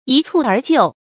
注音：ㄧ ㄘㄨˋ ㄦˊ ㄐㄧㄨˋ
一蹴而就的讀法